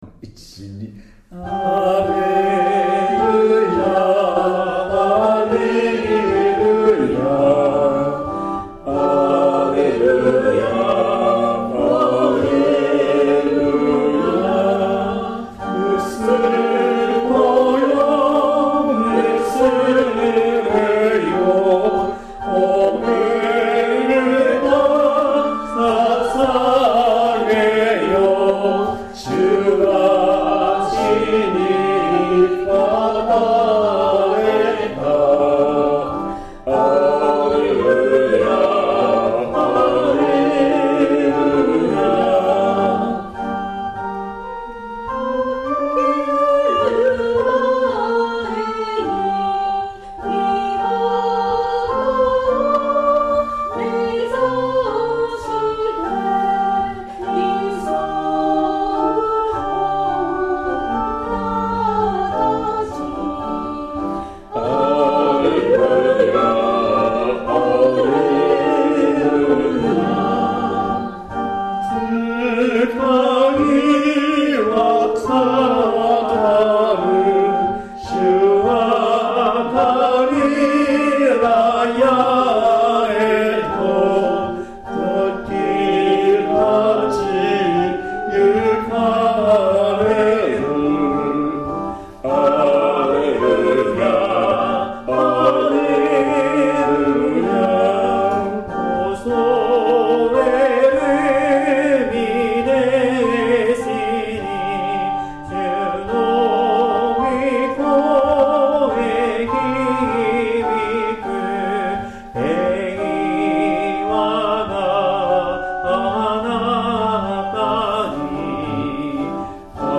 ♪聖歌隊練習補助
Tonality = g　Pitch = 440
◆練習での録音
♪ Pre 　1. Unison　 2.女声 　3.男声　4.T.ソロ 　5.Unison
+Reverve:S-Room